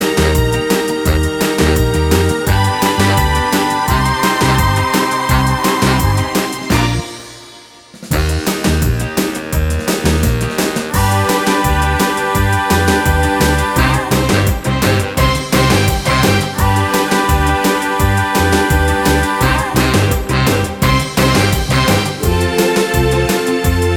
No Harmony Pop (2010s) 2:38 Buy £1.50